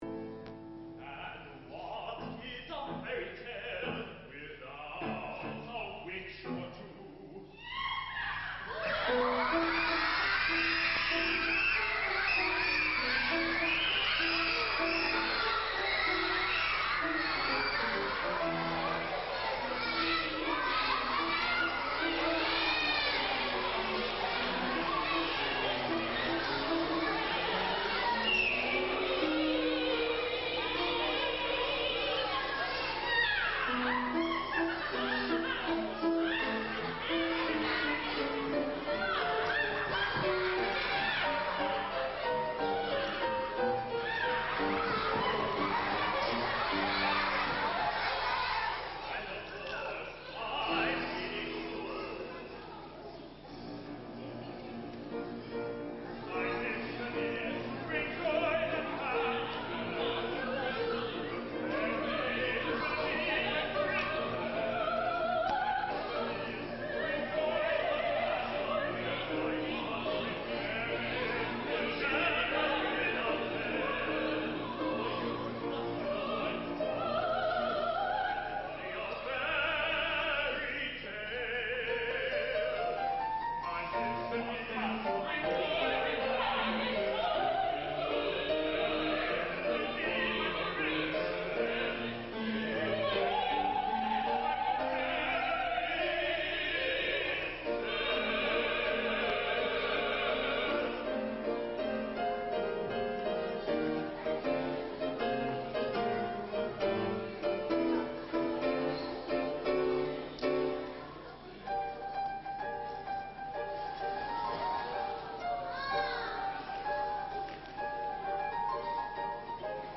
a one-act opera for young audiences